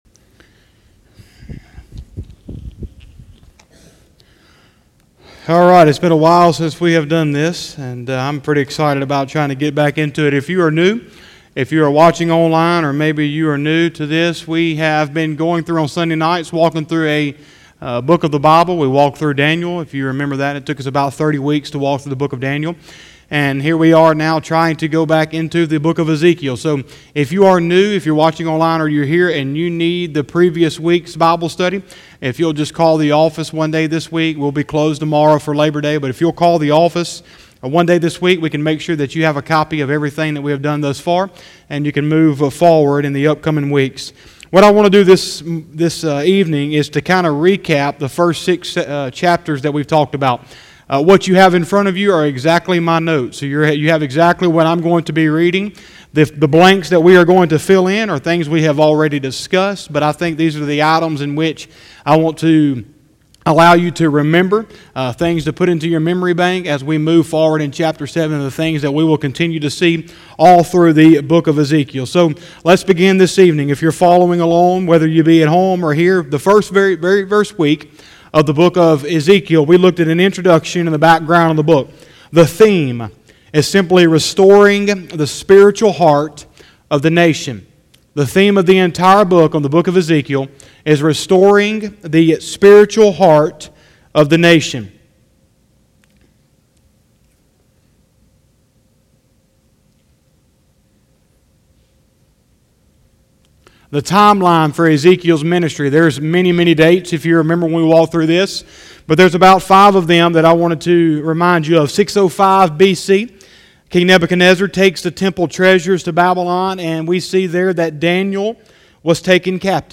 09/06/2020 – Sunday Evening Service